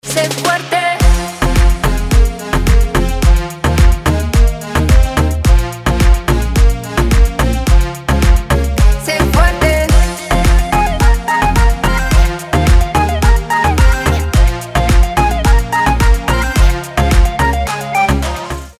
поп
красивые
заводные
dance